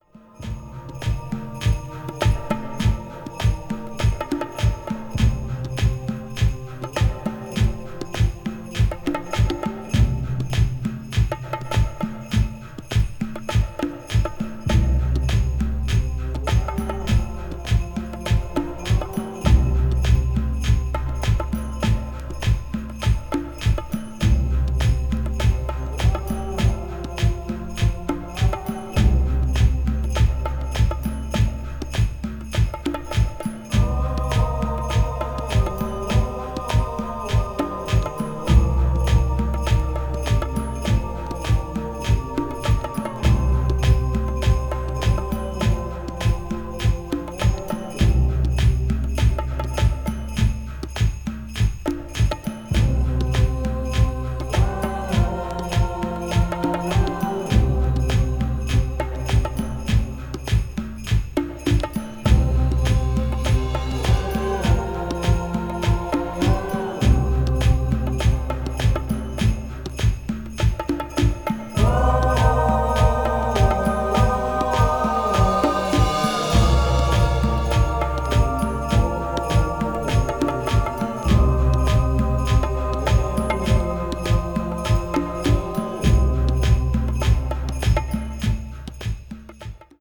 ズシッと腰にくるローなビートにアレンジされています。